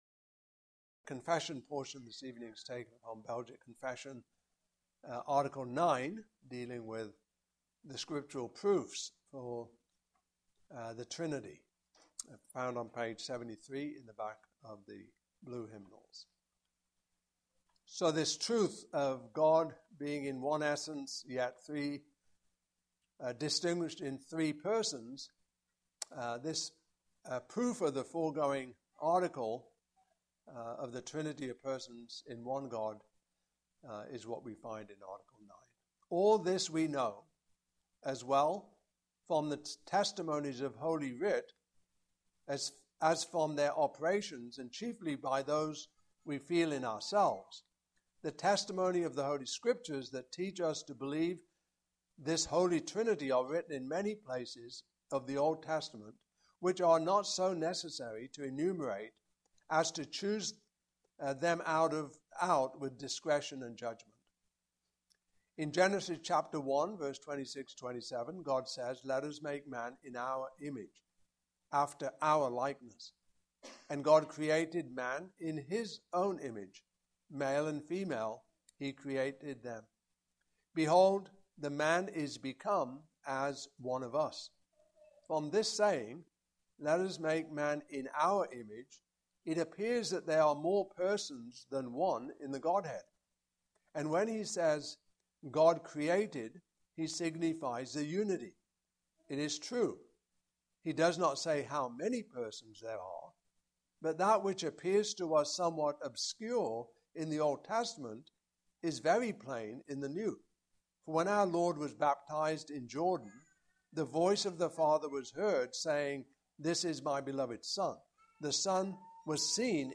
Passage: John 1:19-34 Service Type: Evening Service